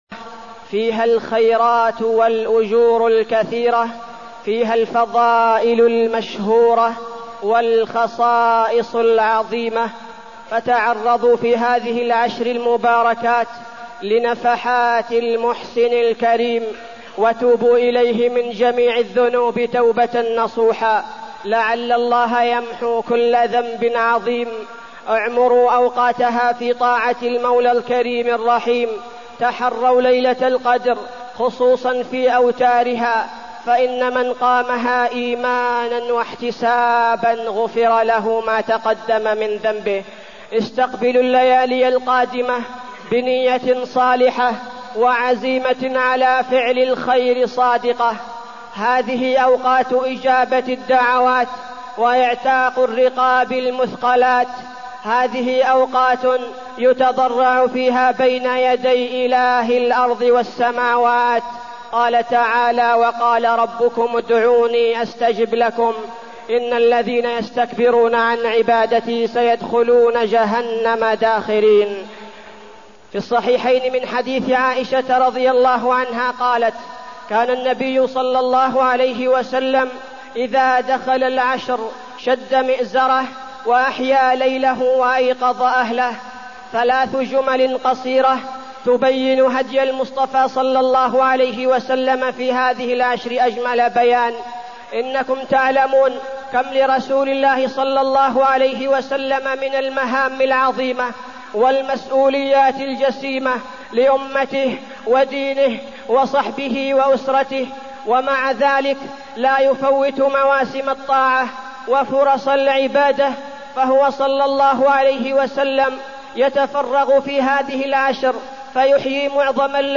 تاريخ النشر ٢٣ رمضان ١٤٢٠ هـ المكان: المسجد النبوي الشيخ: فضيلة الشيخ عبدالباري الثبيتي فضيلة الشيخ عبدالباري الثبيتي العشر الأواخر والألفية